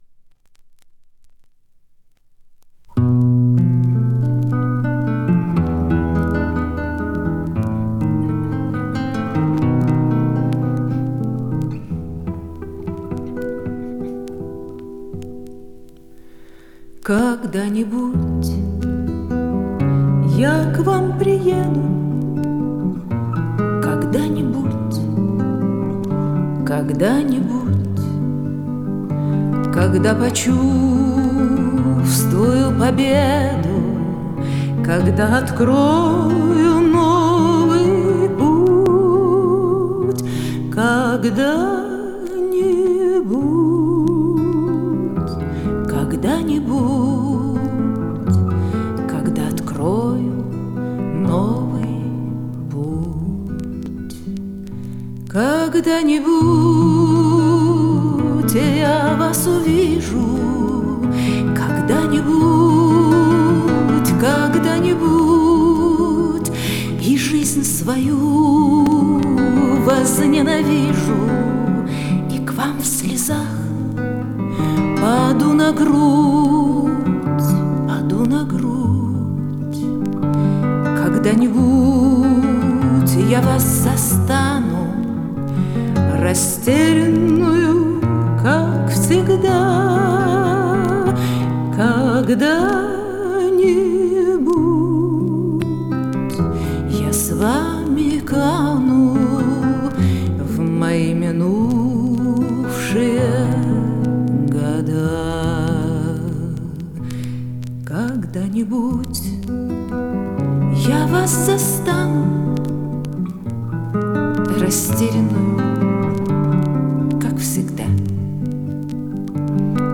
Жанр: Romance